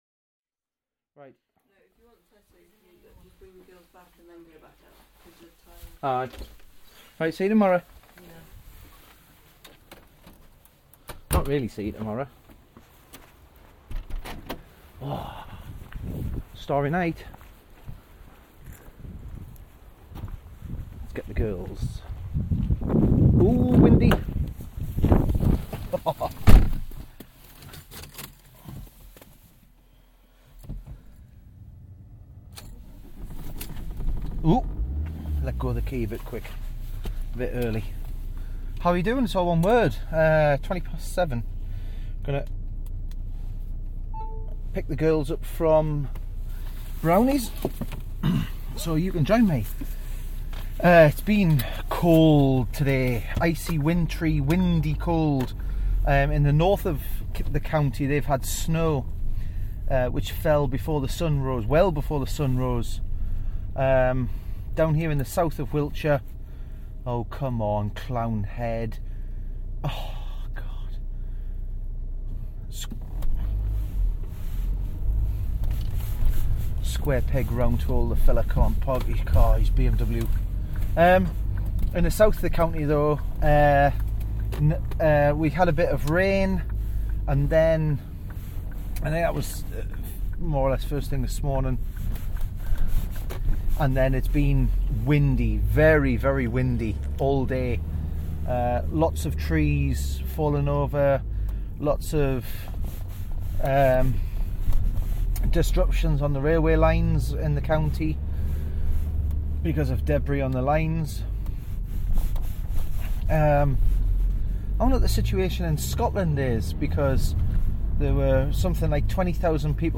Come with me dear listeners, come with me on the binaural journey...